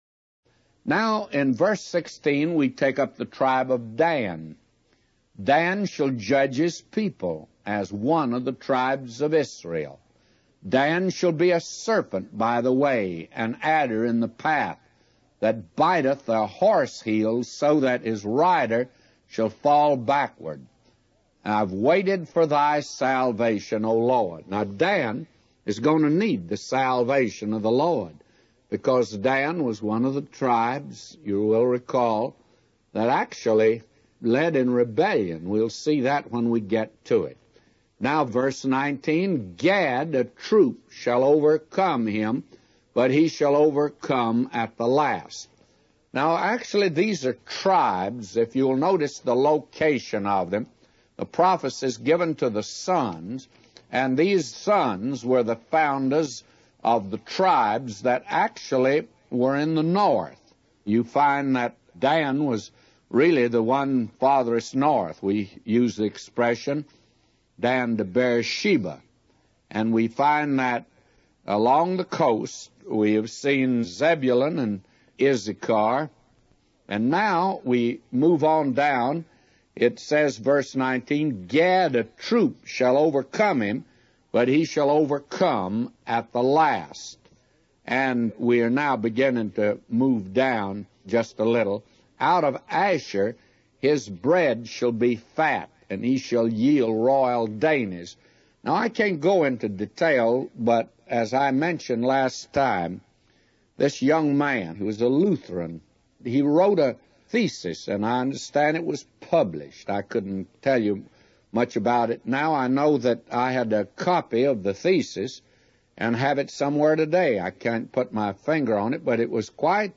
A Commentary By J Vernon MCgee For Genesis 49:16-999